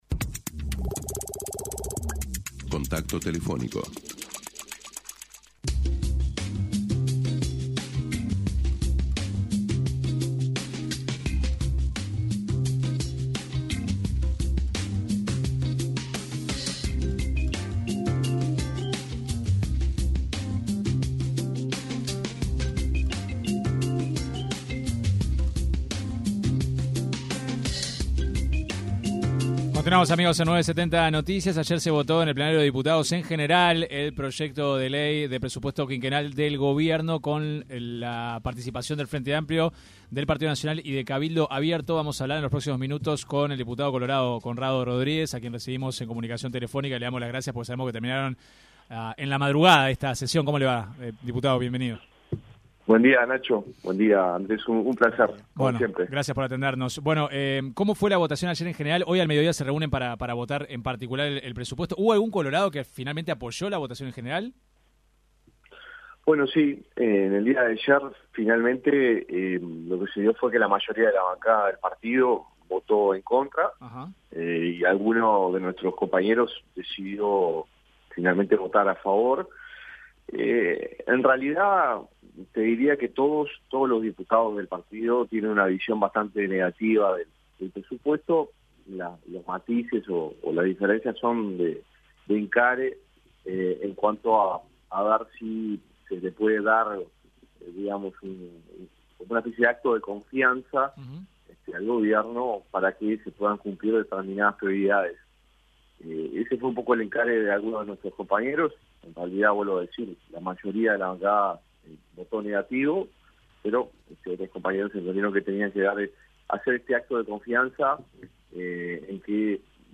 El diputado colorado Conrado Rodríguez explicó en entrevista con 970 Noticias el motivo de su voto en contra.